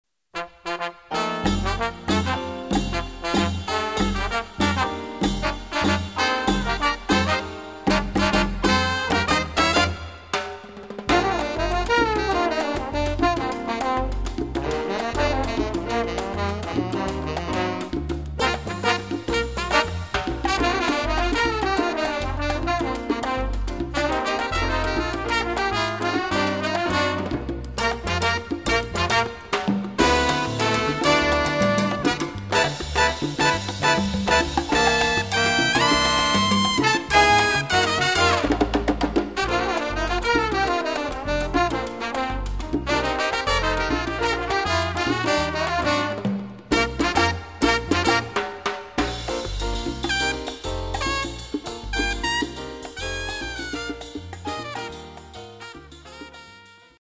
Category: little big band
Style: mambo
Solos: open